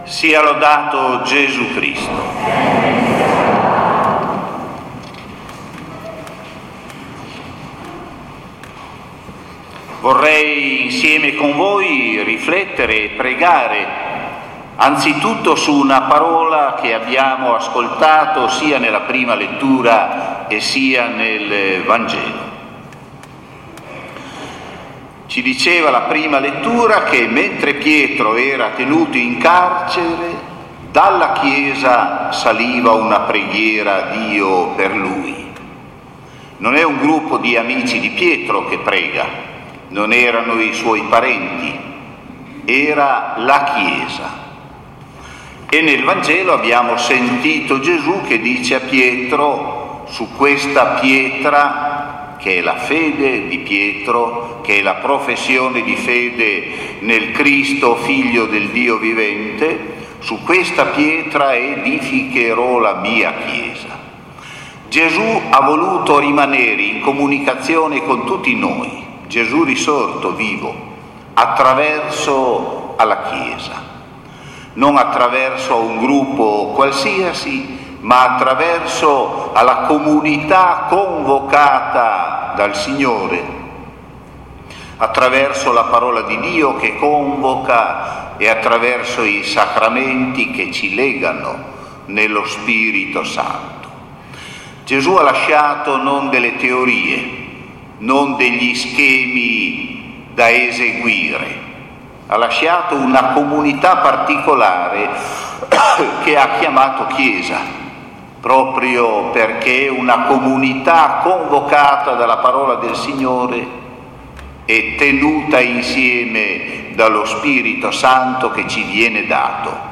Omelia di S.E.R. Mons. Arrigo Miglio, Arcivescovo Metropolita di Cagliari, della festa dei Santi Patroni della Parrocchia Pietro e Paolo.